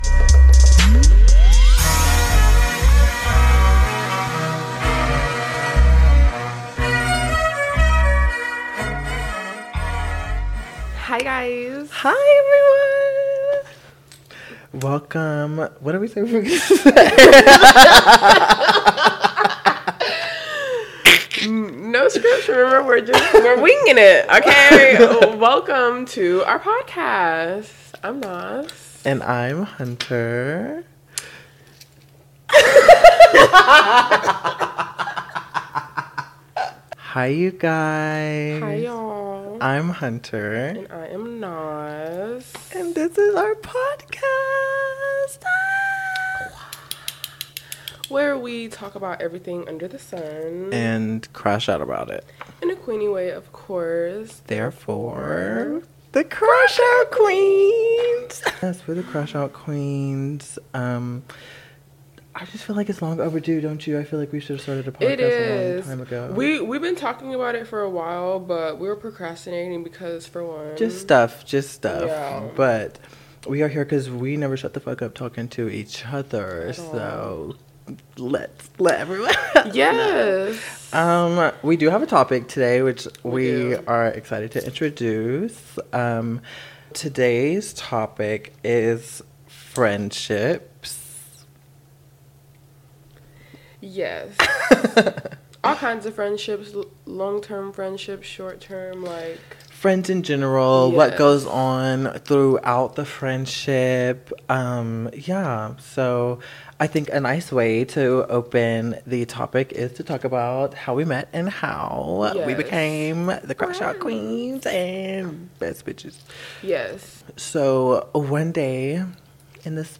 Play Rate Listened List Bookmark Get this podcast via API From The Podcast CrashOut Queens is your new favorite chaos corner where a hot Black girl and her gay best friend pick a topic, pour some wine, and unapologetically crash out.
Expect bold opinions, big laughs, and the kind of real talk that'll make you feel like you're on FaceTime with your loudest besties.